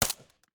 sounds / weapons / _bolt / smg9_1.ogg